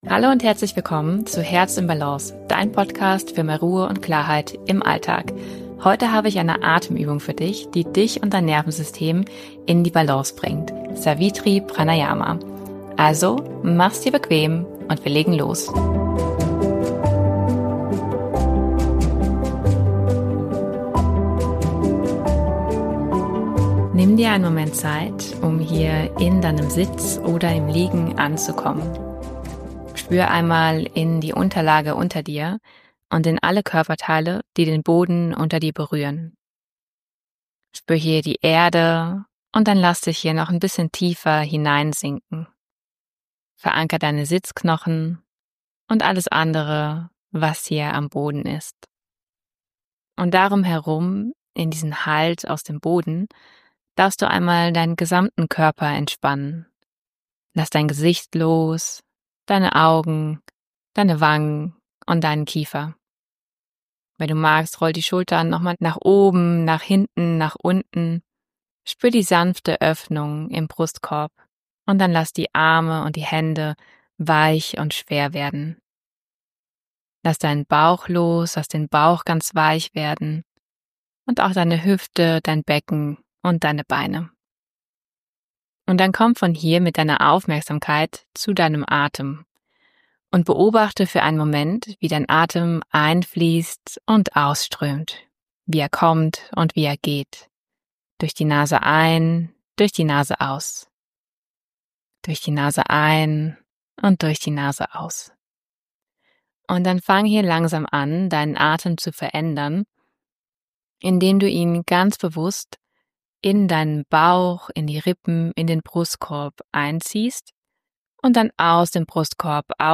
In dieser Episode führe ich dich durch die kraftvolle Atemübung Savitri Pranayama, die dir hilft, dein inneres Gleichgewicht wiederzufinden und mehr Ruhe in dein Leben zu bringen.